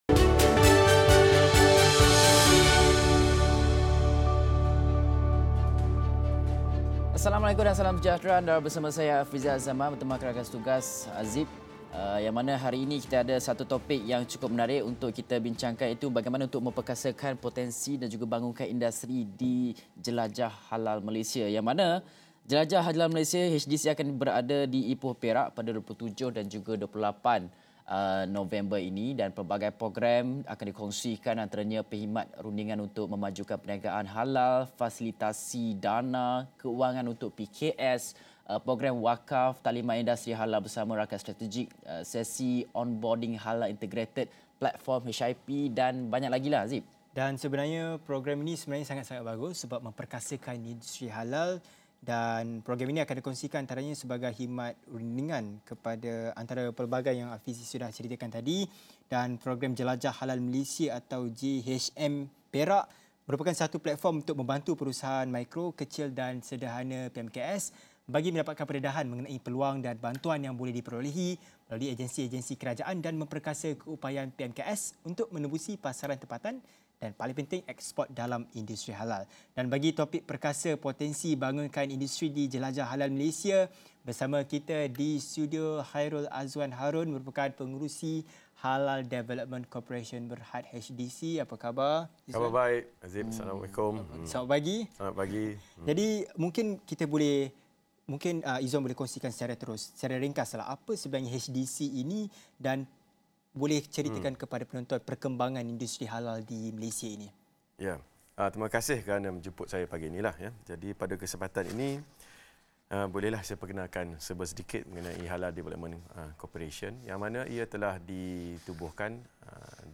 Diskusi 8.30 pagi ini bersama Pengerusi Halal Development Corporation Berhad (HDC), Khairul Azwan Harun yang akan perincikan bagaimana program Jelajah Halal Malaysia akan membantu perkasa potensi industri halal.